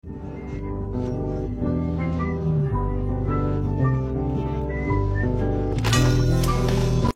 Música de fundo